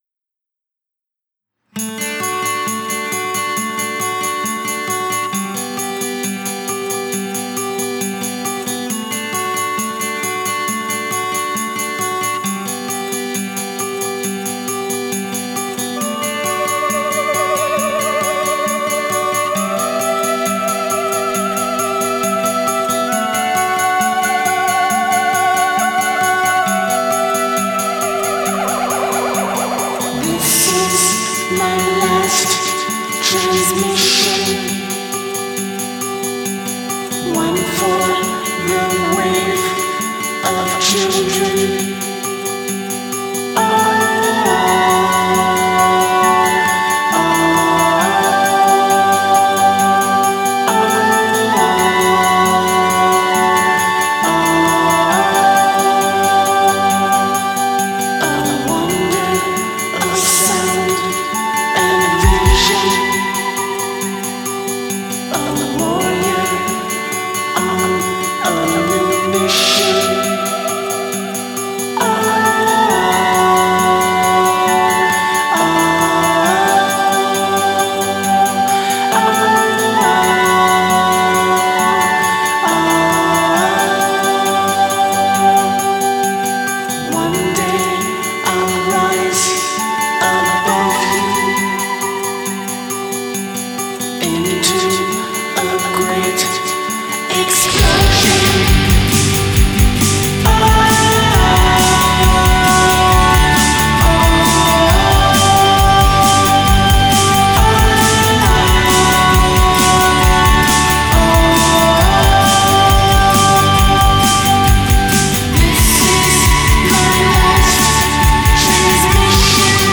you’ll hear the same guitar riff.
Vocals, Synth
Bass, Vocals